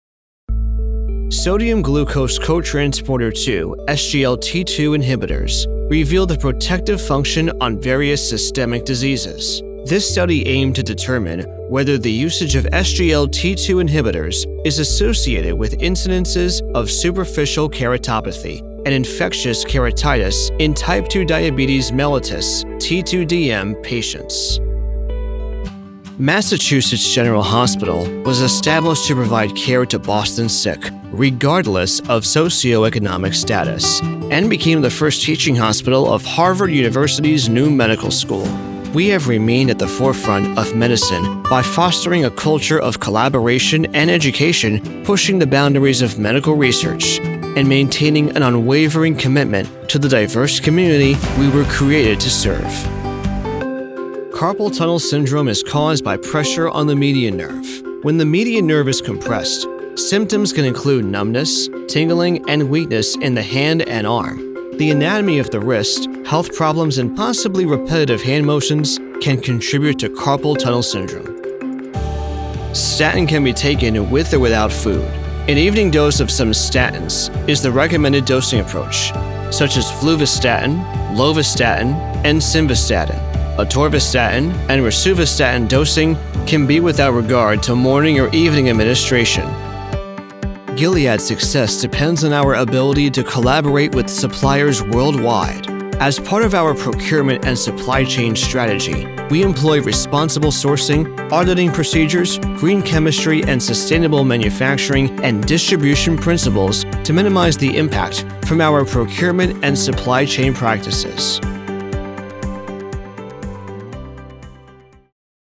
Medical Narration Demo
English-North American, English-Neutral
Young Adult